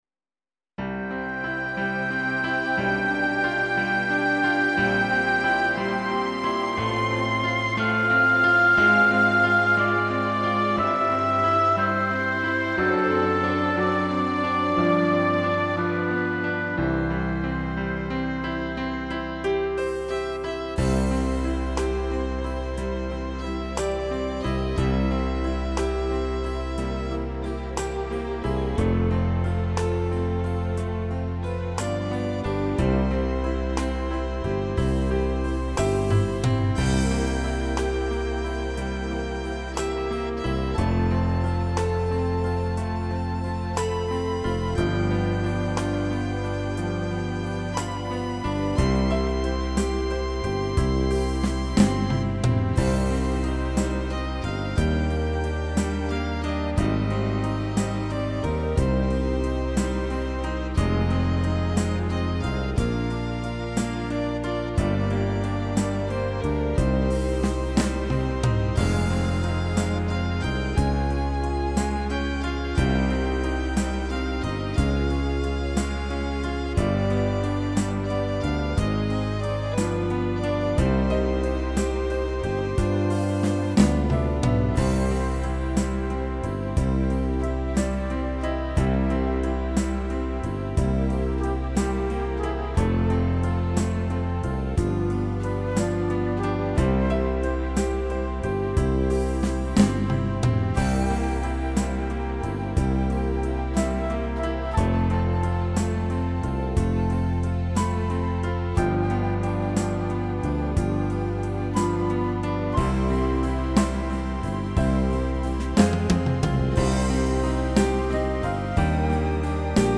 Tone Nữ (Cm)